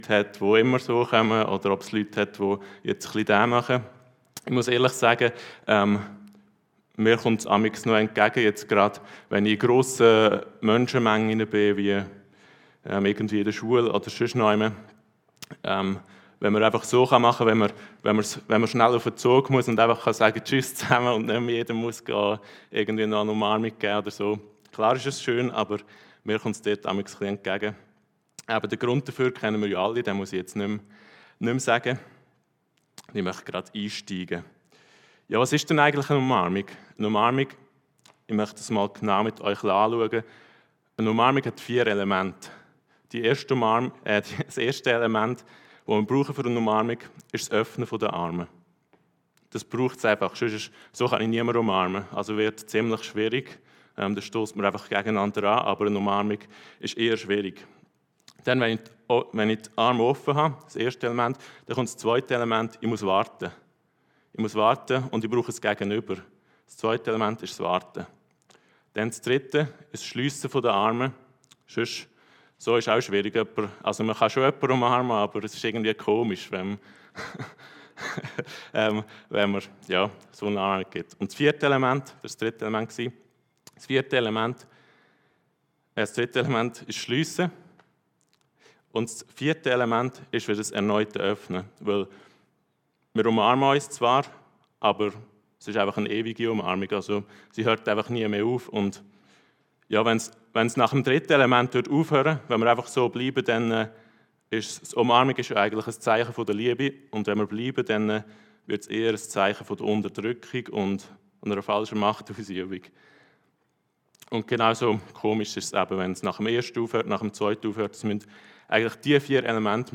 Predigten Heilsarmee Aargau Süd – Die Umarmung Gottes